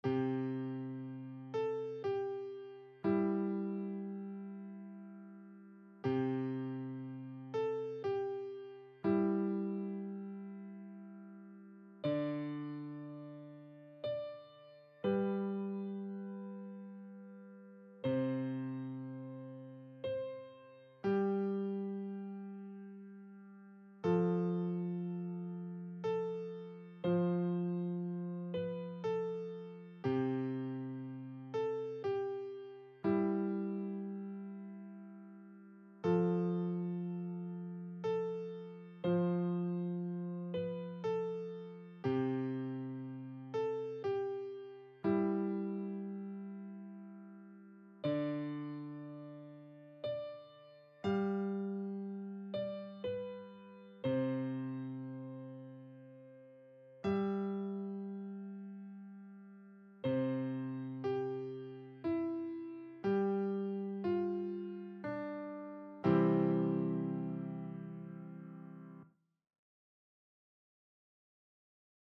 Piano
Douce Nuit - Piano Débutant 60bpm.mp3